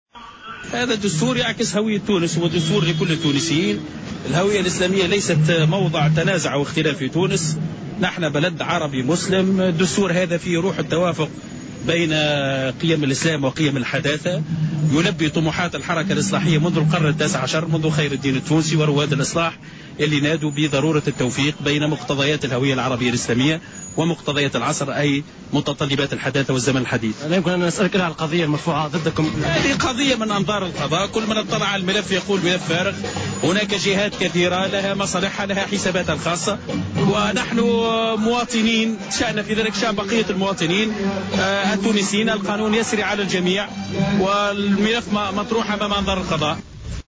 L'ex ministre des Affaires étrangères Rafik Abdessalem a déclaré sur les ondes de Jawhara Fm, en marge de la cérémonie organisée par le mouvement Ennahdha à la place du Bardo, que le dossier de l'affaire du don chinois est vide accusant certaines parties d'avoir provoqué l'affaire pour des intérêts personnels.